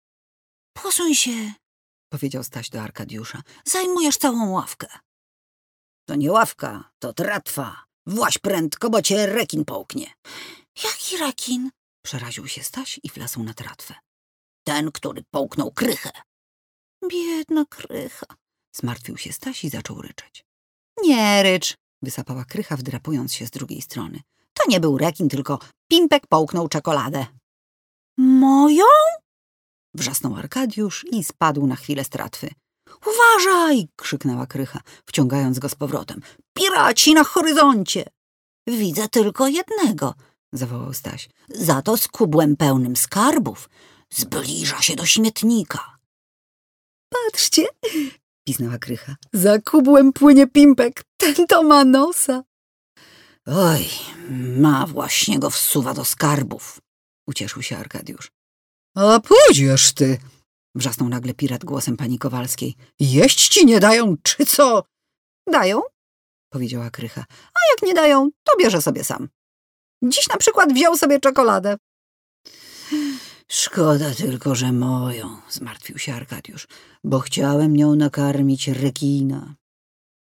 Female 30-50 lat
polski · Turnaround: 48h · Reklama Narracja do filmu Audiobook
Nagranie lektorskie